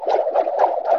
sling_swing.wav